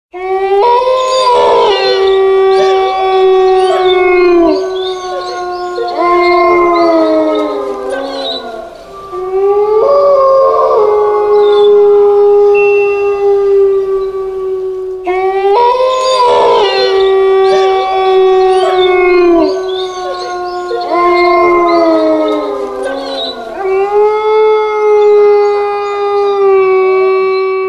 Wolf klingelton kostenlos
Kategorien: Soundeffekte